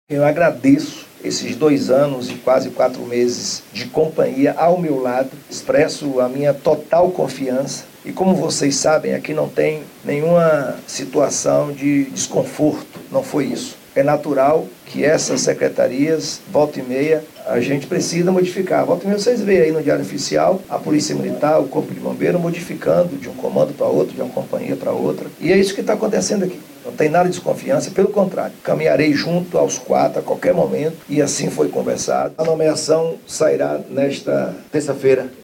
🎙Sonora Governador Jerônimo Rodrigues
Em coletiva nesta segunda (24), no Centro de Operações e Inteligência, em Salvador, foram revelados os novos integrantes da cúpula da Segurança Pública que passam a comandar a Polícia Militar, a Polícia Civil, o Corpo de Bombeiros e a Polícia Técnica.